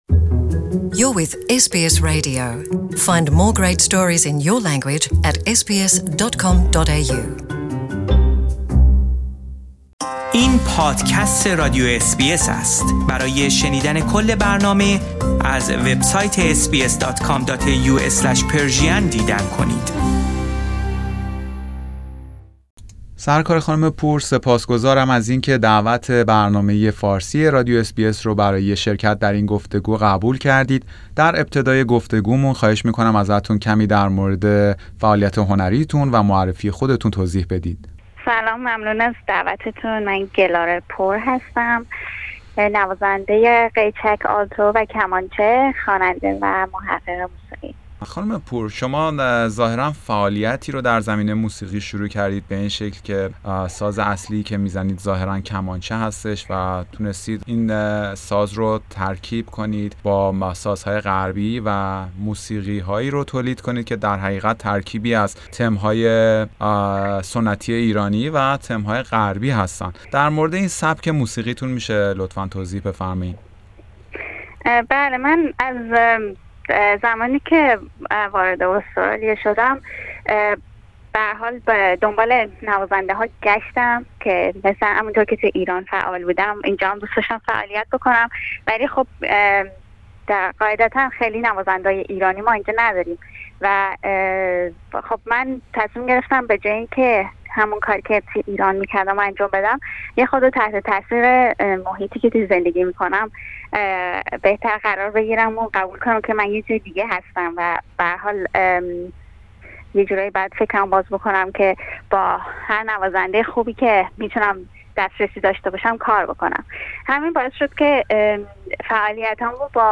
او در گفتگو با رادیو اس بی اس فارسی از خود و تجربه موسیقیایی اش می گوید.